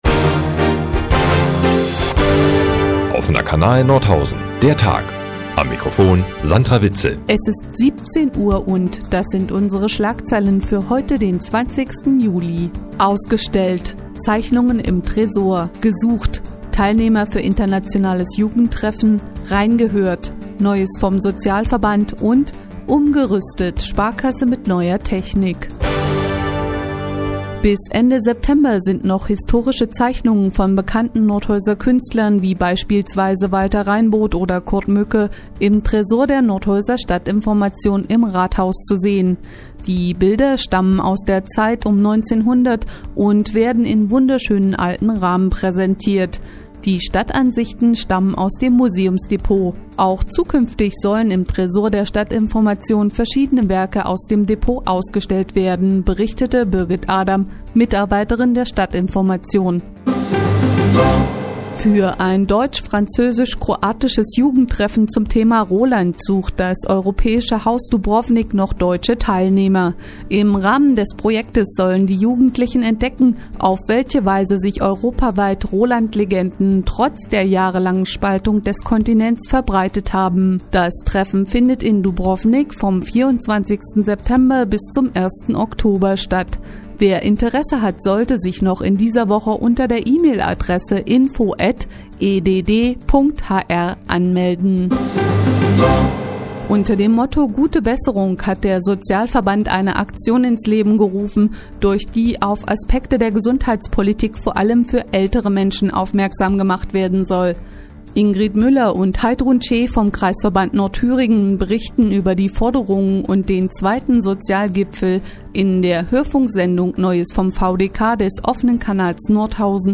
Die tägliche Nachrichtensendung des OKN ist nun auch in der nnz zu hören. Heute geht es um Kunst und Jugend, Geld und Soziales.